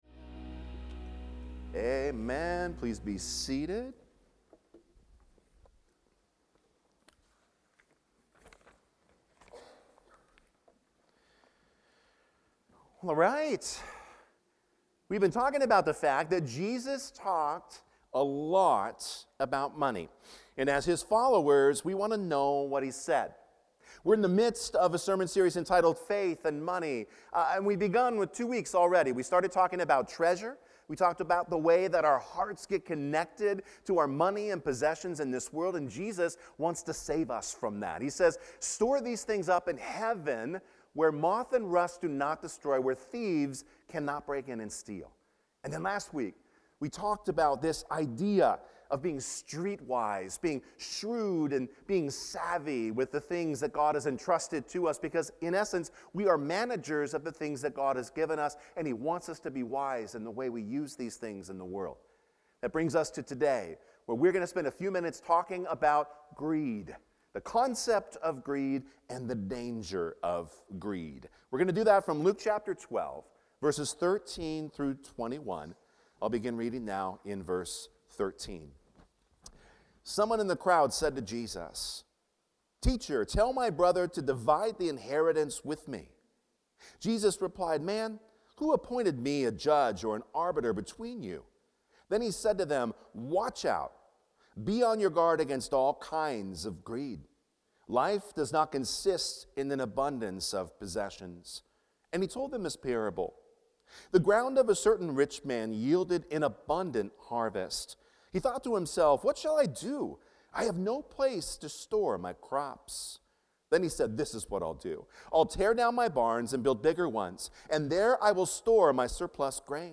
Faith & Money: Greed | Fletcher Hills Presbyterian Church